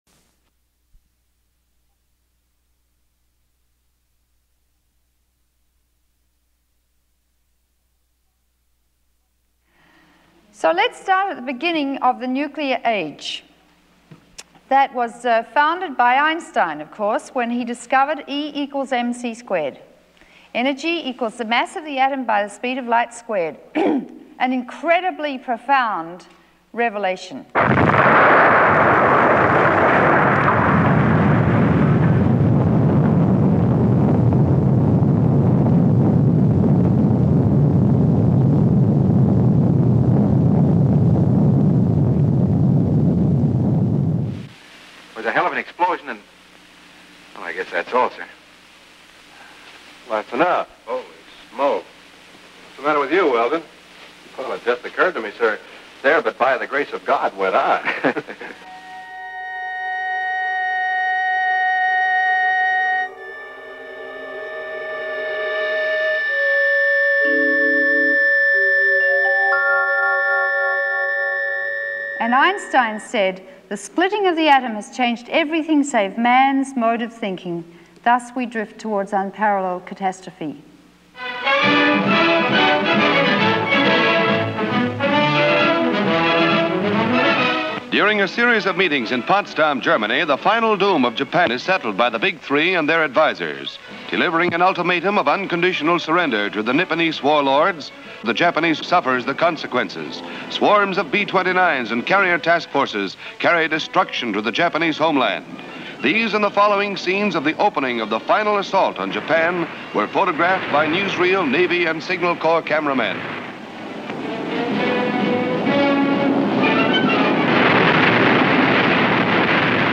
In this war, there will be no winners everybody dies, period Mirrored Content This Oscar®-winning short film is comprised of a lecture given to students by outspoken nuclear critic Dr. Helen Caldicott, president of Physicians for Social Responsibility in the USA. Her message is clear: disarmament cannot be postponed.